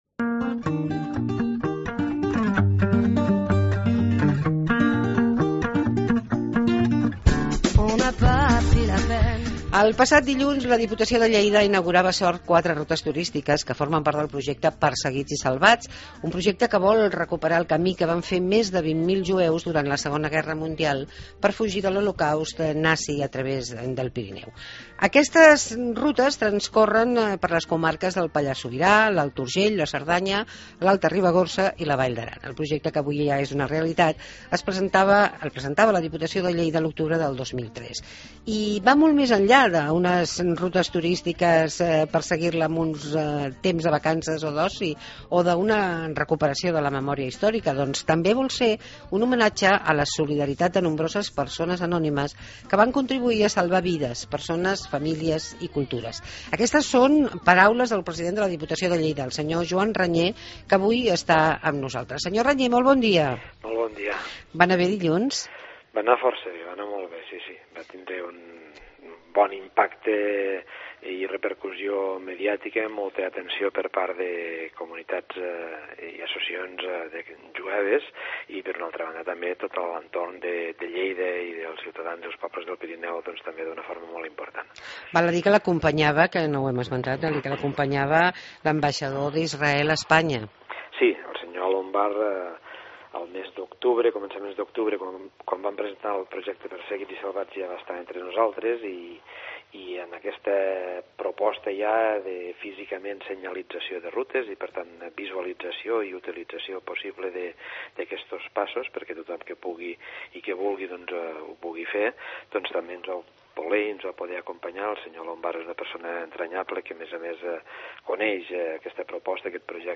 Joan Reñé, president de la Diputació de Lleida ens parla de "La ruta dels jueus"